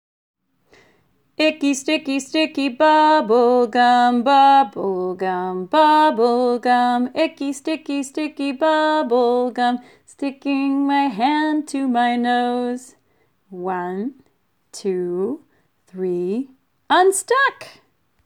(Click on the triangle to hear the tune)